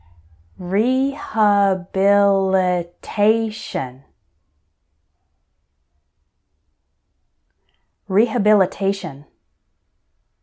So for these words I’ll say them once slowly and once normally, so you can repeat both times.
re – ha – bi – li – TA – tion………. rehabilitation